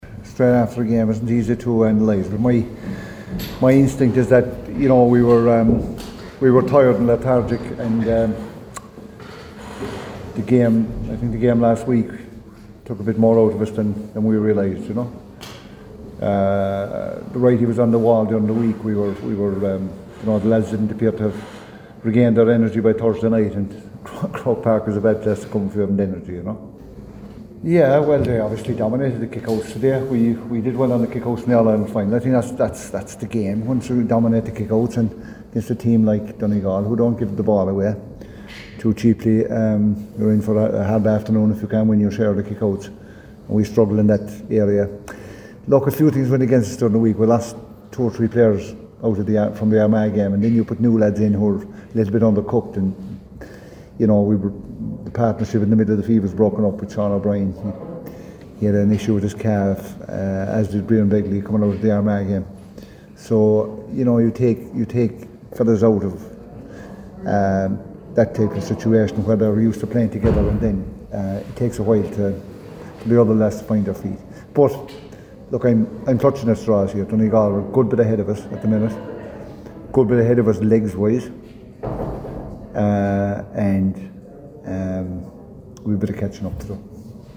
The Kerry boss spoke to the assembled media after today’s 3-20 to 2-10 loss…